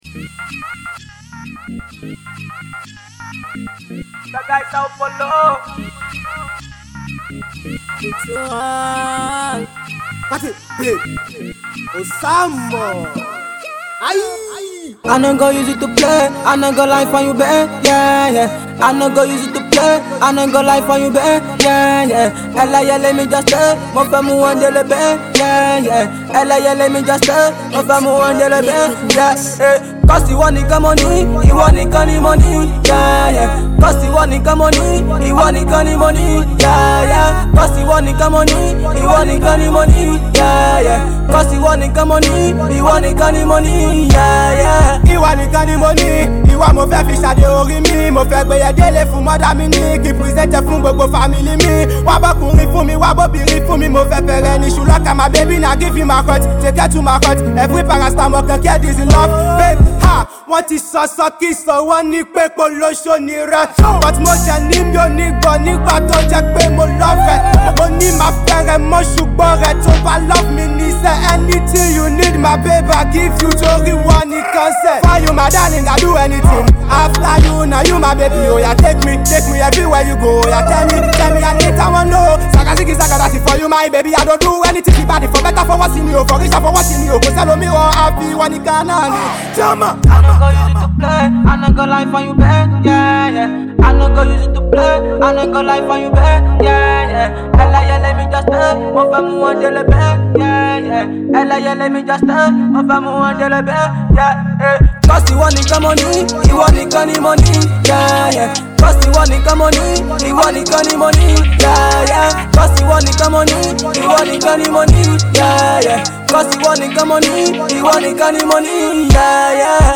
African Music is about to take over 2020.